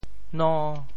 挪威 潮语发音 展开其他区域 潮州 no5 ui1 潮州 0 1 中文解释 挪威 挪威王国（挪威语：Kongeriket Norge或 Kongeriket Noreg），简称“挪威”（挪威语：Norge 或Noreg），意为“通往北方之路”，是北欧五国之一，位于斯堪的纳维亚半岛西部。